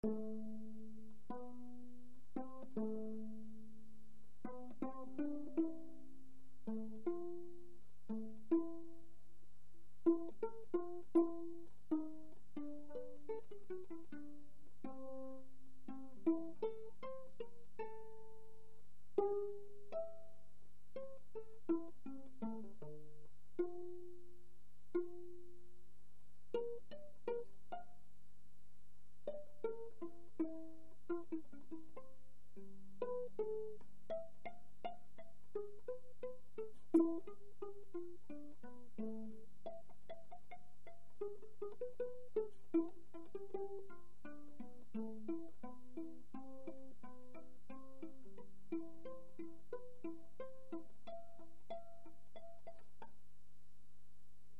Musique classique